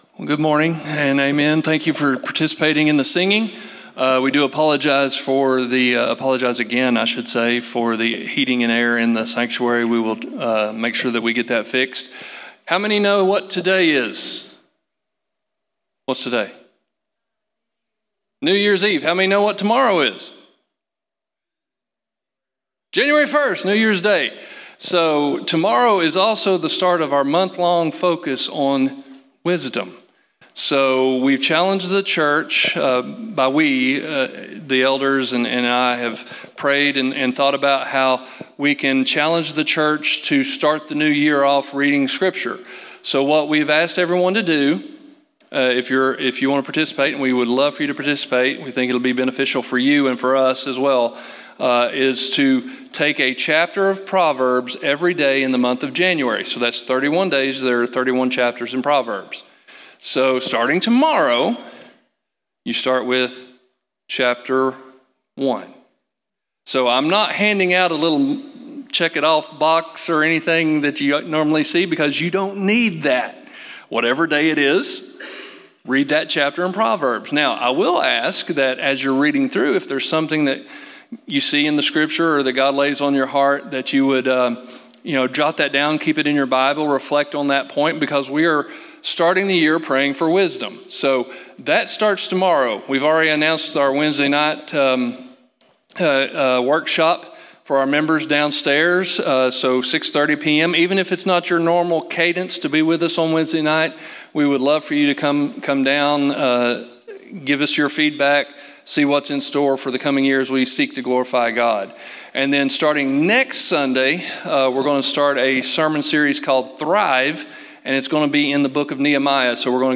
In today's sermon, entitled "Resolved", we look at each phrase from Acts 20:24 in detail.